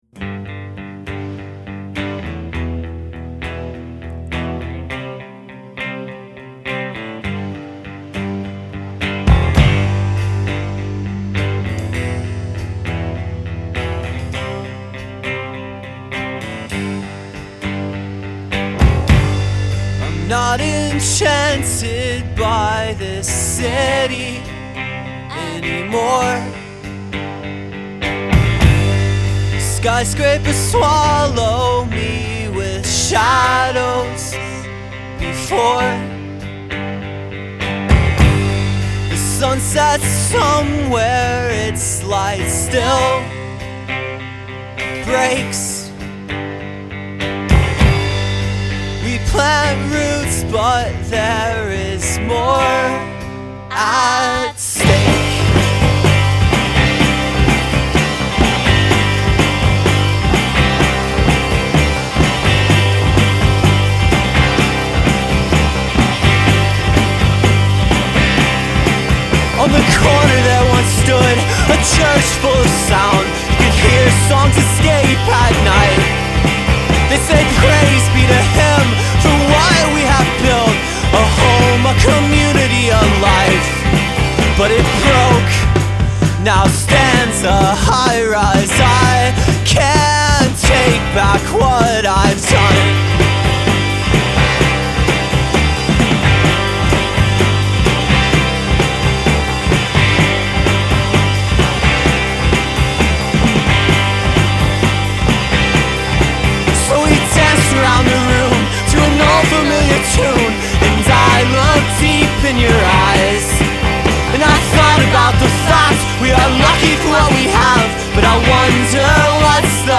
guitar, harmonica, and vocals
banjo and more
synth and vocals
lead guitar